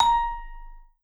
Index of /90_sSampleCDs/Sampleheads - Dave Samuels Marimba & Vibes/VIBE CMB 2C